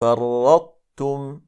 8. Ţā' [الطاء — ط] in i Tā' [التاء — ت]:
Det är ofullständig idgham (إدغامٌ ناقص); eftersom en stark bokstav inte går in helt i en svag bokstav, så brukade araberna slå samman det icke-vokaliska (Ţā' — ط) i (Tā' — ت), samtidigt som det bevarade egenskapen av vidhäftning (iţbāq — الإطباق) från det, vilket händer när reciteraren stänger hans/hennes tunga på en (Ţā' — ط) utan qaqalah och sedan tar han/hon bort den från en vokal (Tā' — ت), som i: